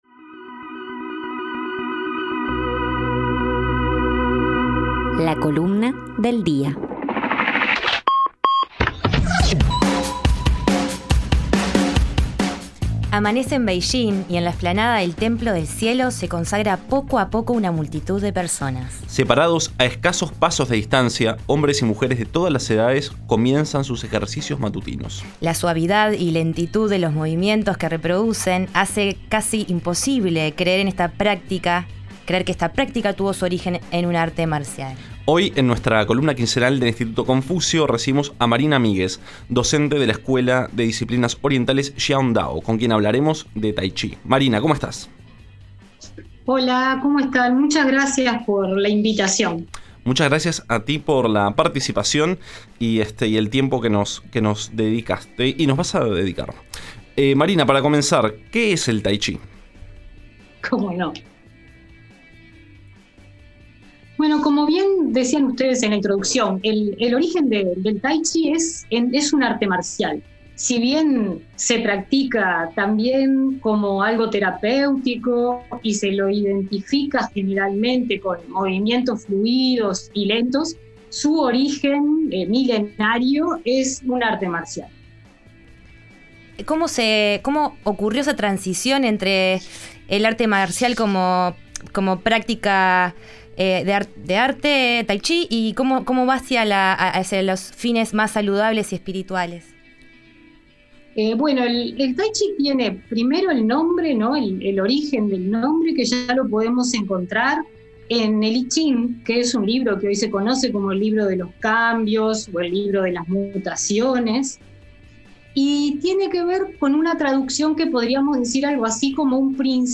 Además, conversamos con él sobre discos, guitarras eléctricas y confinamiento y escuchamos una de sus canciones en vivo.